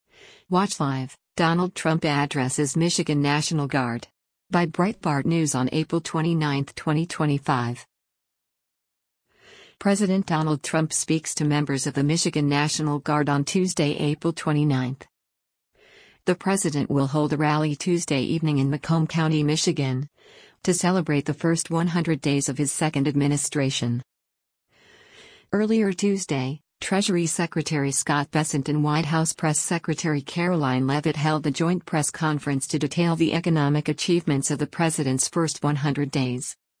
President Donald Trump speaks to members of the Michigan National Guard on Tuesday, April 29.